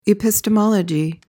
PRONUNCIATION:
(i-pis-tuh-MOL-uh-jee)